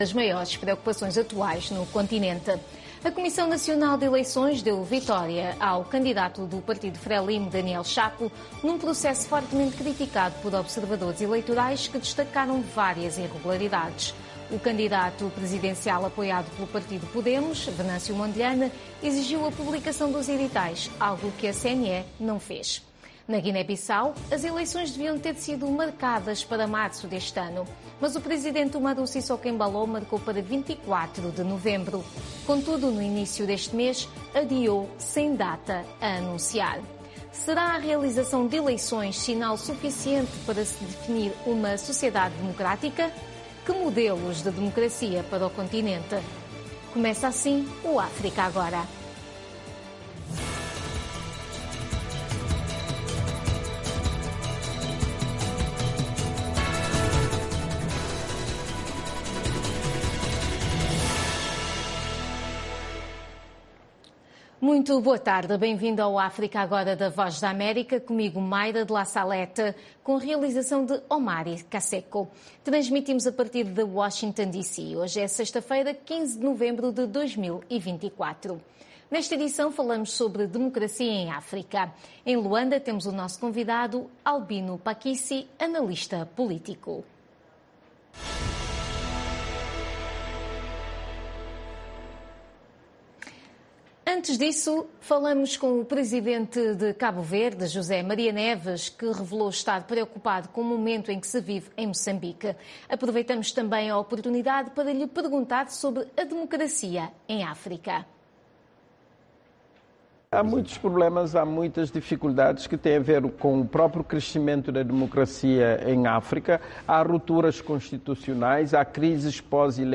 Um debate sobre temas actuais da África Lusófona.